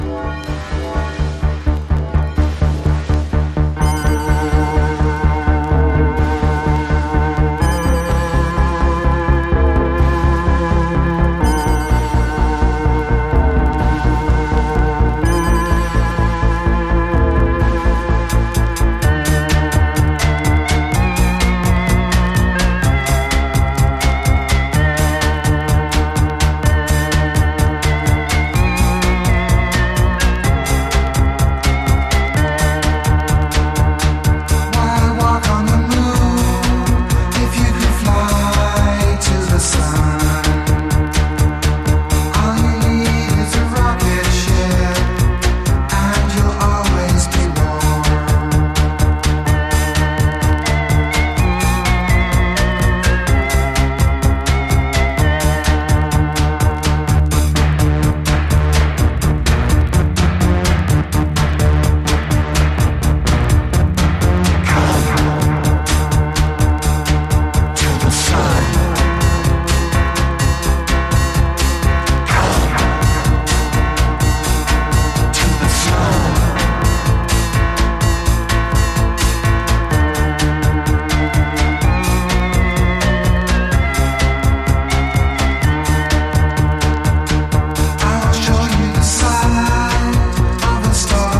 キュートな電子音＋脱力ヴォーカルの中毒性は相当なもの。